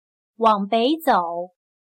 往北走/wǎng běi zǒu/ir al norte